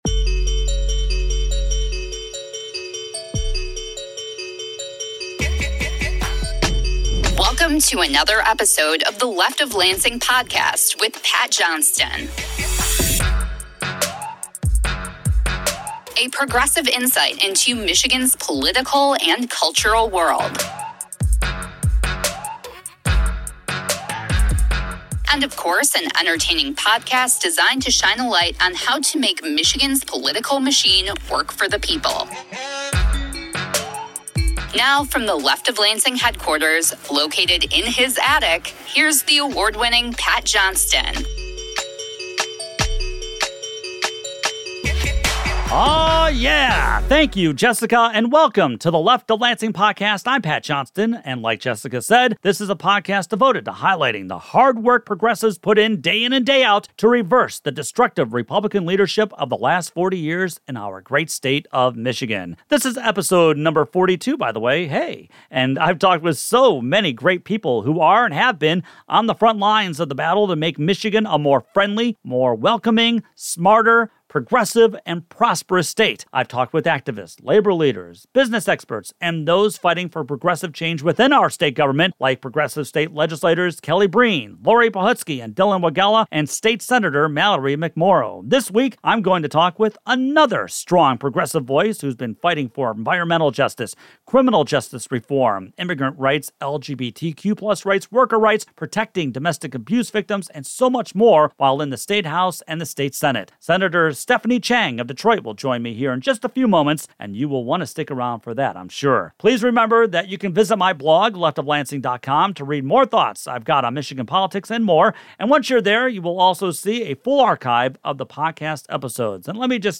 Episode 42: Interview with State Senator Stephanie Chang
Then, Democratic state Senator Stephanie Chang joins me to discuss how the Democratic majority in the state legislature is getting stuff done on environmental justice, education, LGBTQ+ rights, voting rights, hate crimes, and the state budget.
The "Last Call" highlights a speech by Democratic State Representative Laurie Pohutsky, who introduced a house resolution declaring June as Pride Month.